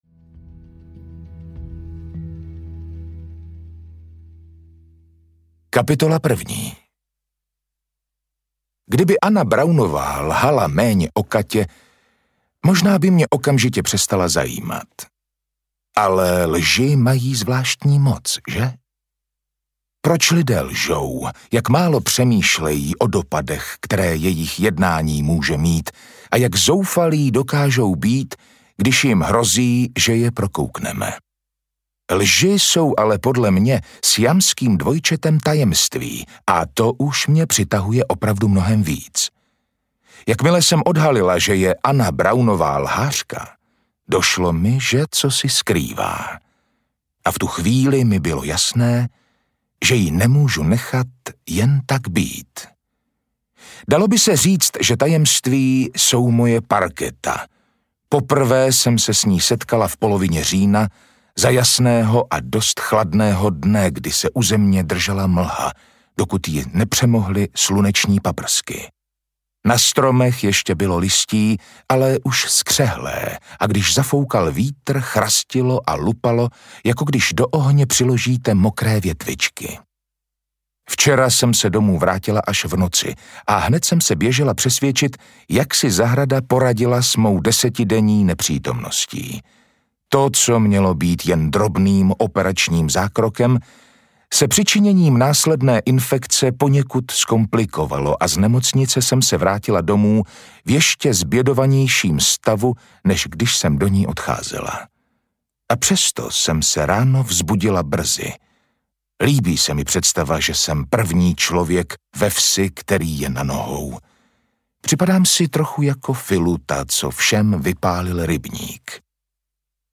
Kdo tě sleduje? audiokniha
Ukázka z knihy